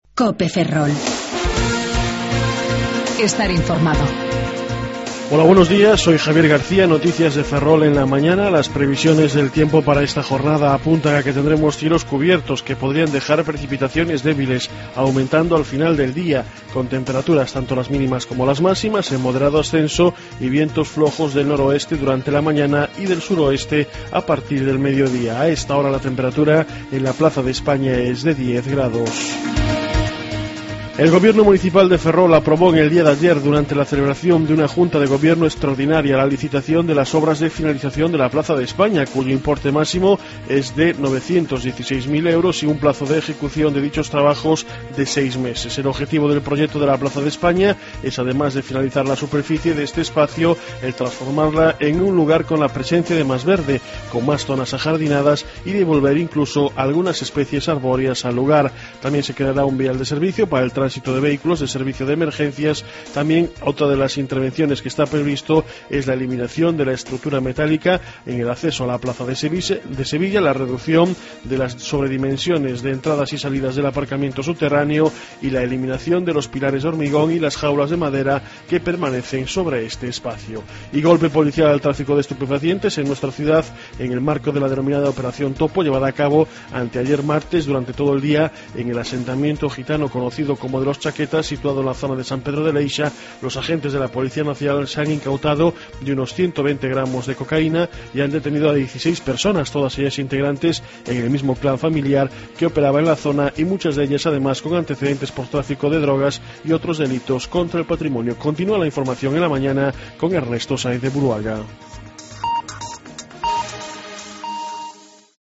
08:28 Informativo La Mañana